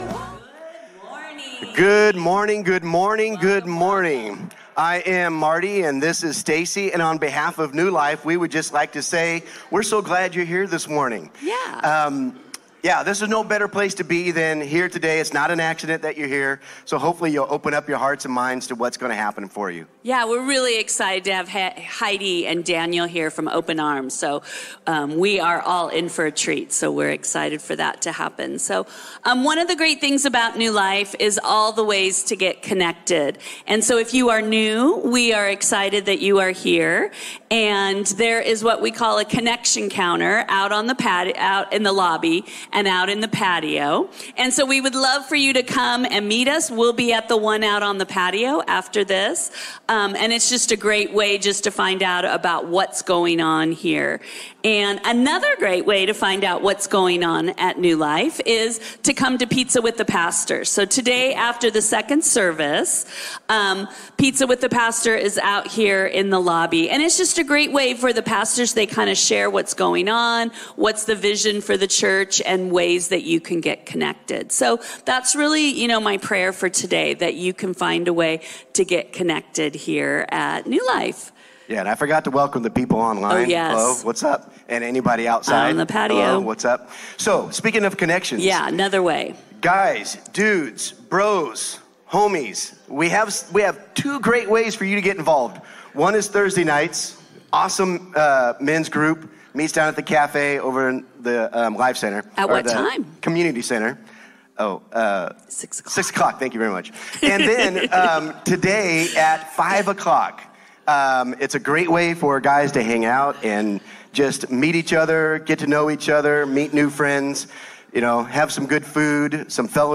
A message from the series "Cultivate."